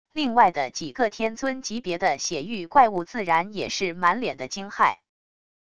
另外的几个天尊级别的血狱怪物自然也是满脸的惊骇wav音频生成系统WAV Audio Player